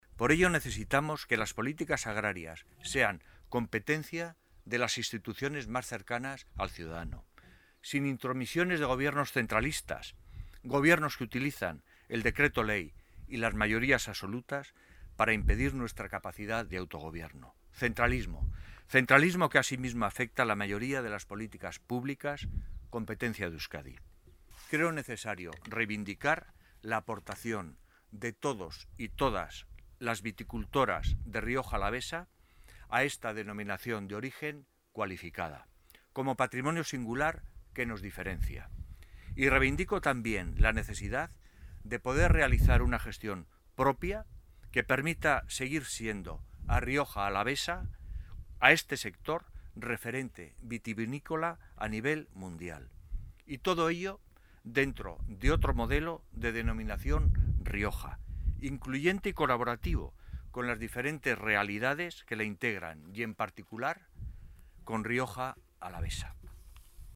Acompañados por el viceconsejero de Agricultura, Bittor Oroz, los candidatos jeltzales alaveses han participado en un acto político en la localidad riojano alavesa de Páganos.